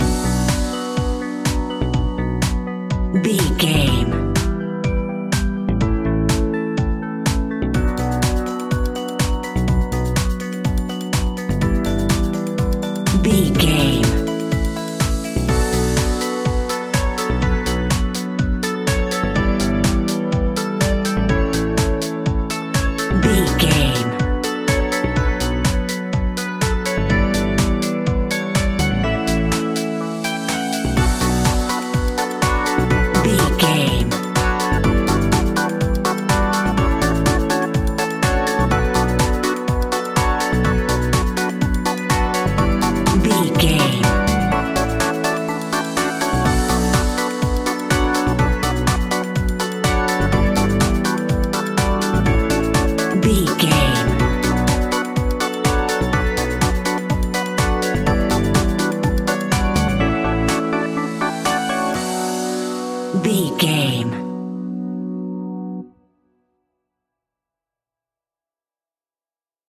Aeolian/Minor
groovy
hypnotic
uplifting
synthesiser
drum machine
electric guitar
funky house
nu disco
upbeat
funky guitar
wah clavinet
synth bass